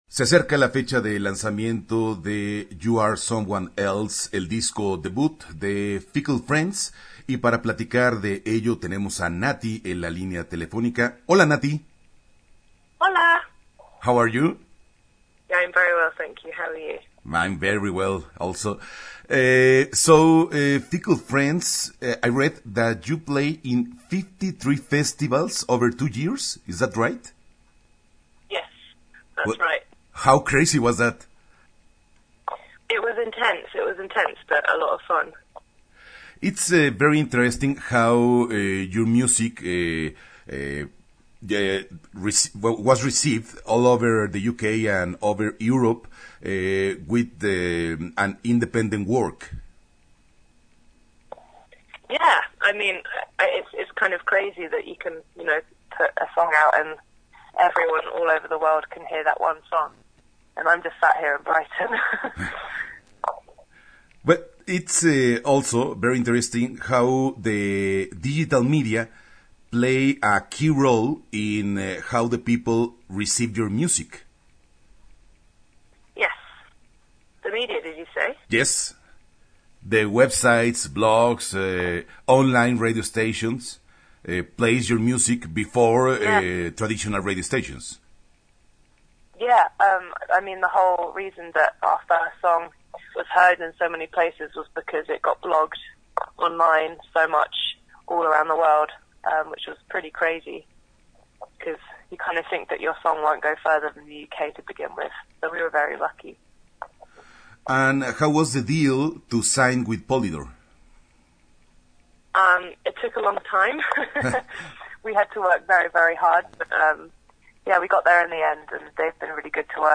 Fickle Friends – Entrevista exclusiva
Entrevista-Fickle-Friends-web.mp3